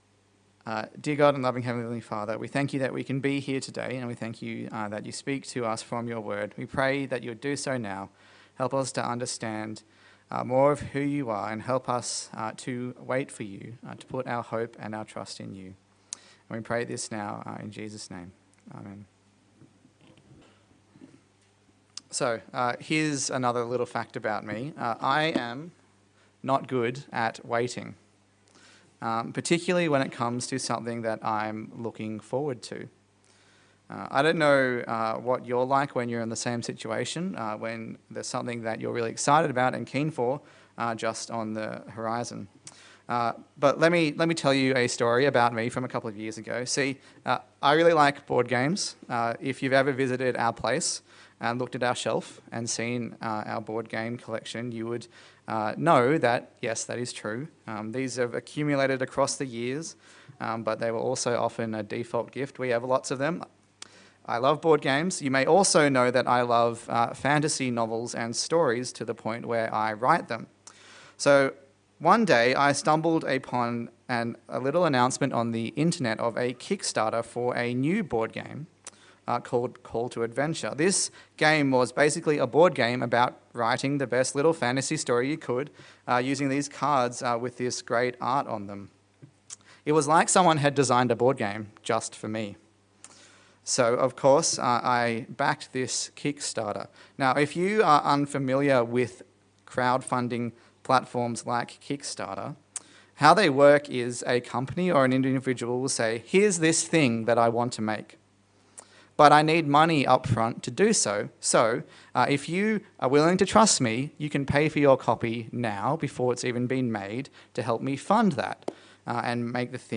Isaiah Passage: Isaiah 40 Service Type: Sunday Service